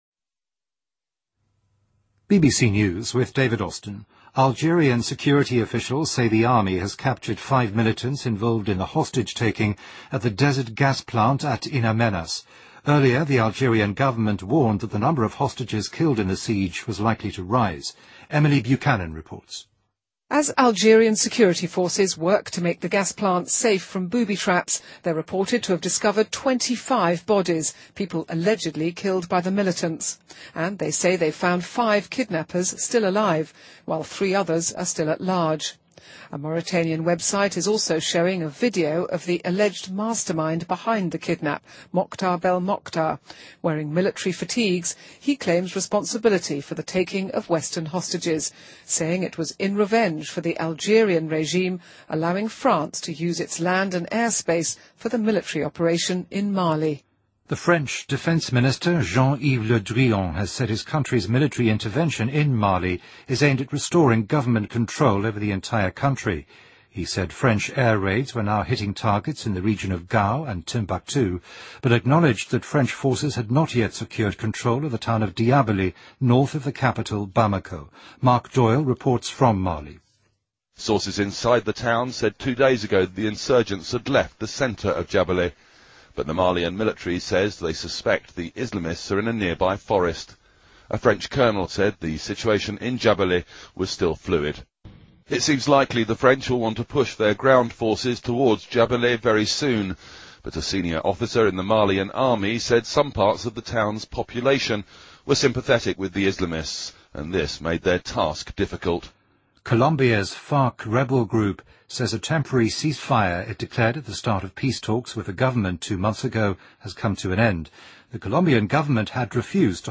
BBC news,联合国称阿富汗监狱仍普遍存在拷打现象